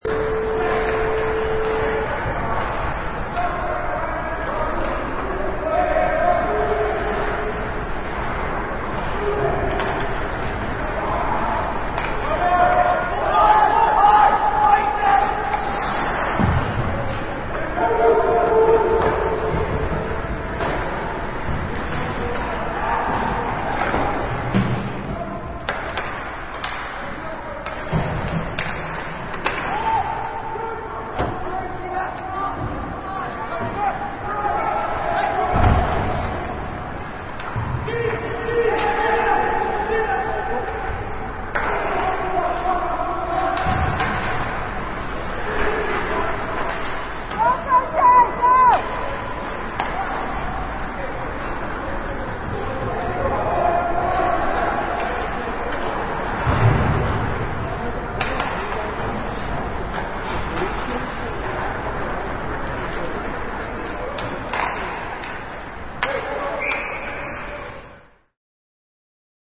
Sound files: Hockey Match 4
Various sounds of a hockey match
Product Info: 48k 24bit Stereo
Try preview above (pink tone added for copyright).
Hockey_Match_4.mp3